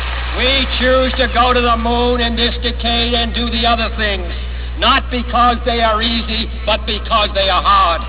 Click to hear JFK talk about the project to the moon